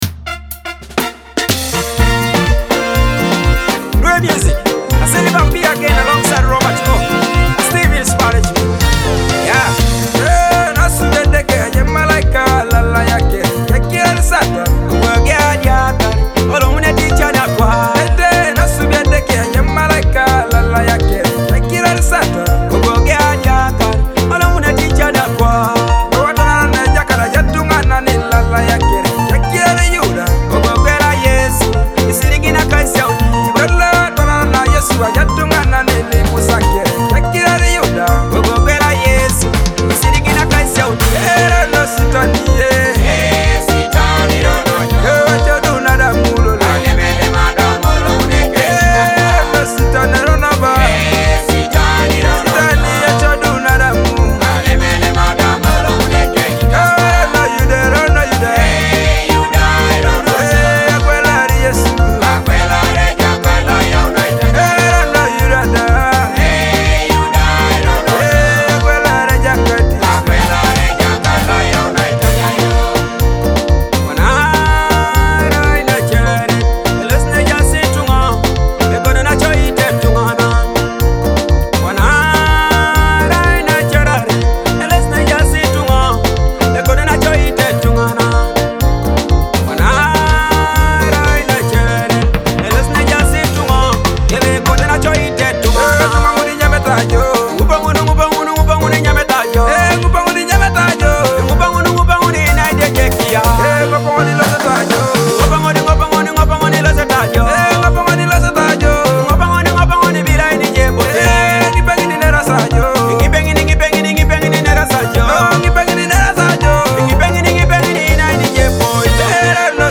a Teso gospel song on creation
Teso praise gospel song